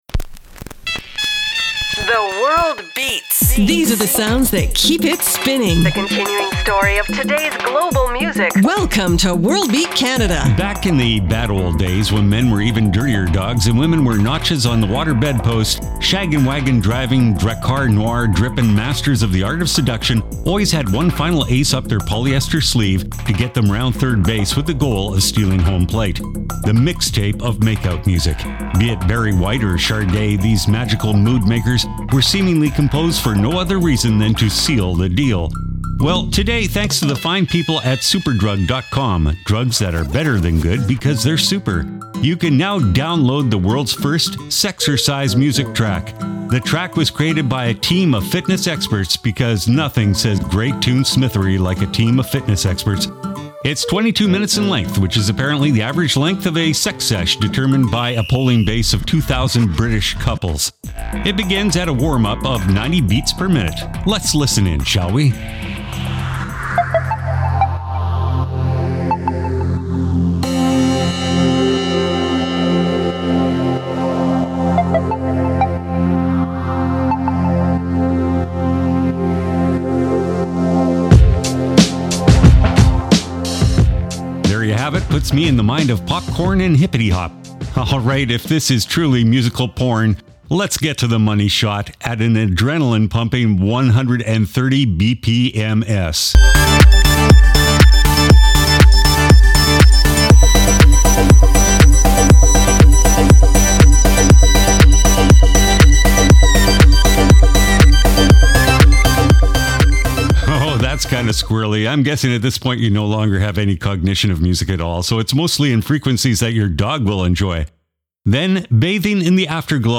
exciting global music alternative to jukebox radio
File Information Listen (h:mm:ss) 0:59:57 worldbeatcanada radio january 23 2015 Download (5) WBC_Radio_January_23_2015.mp3 71,949k 0kbps Stereo Comments: Interview fr. Israel w/ Zvuloon Dub System!